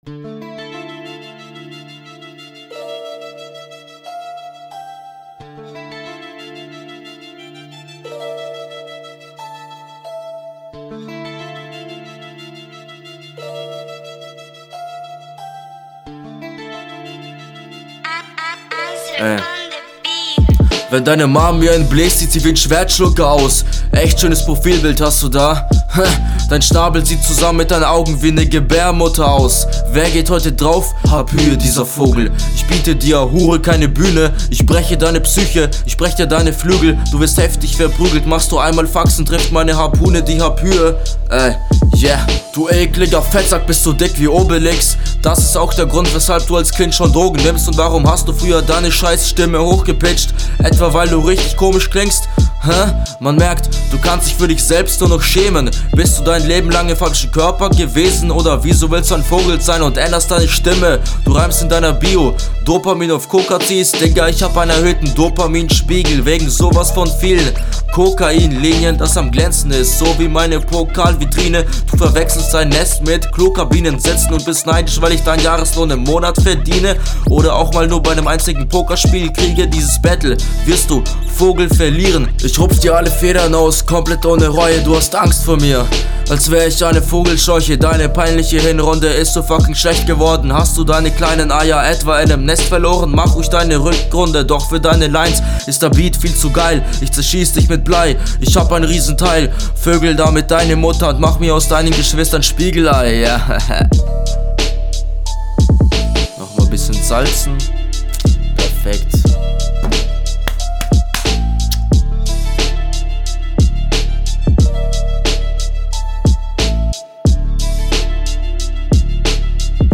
Leider zu häufig neben dem Beat bzw. klingst für mich nicht rund weil meines Erachtens …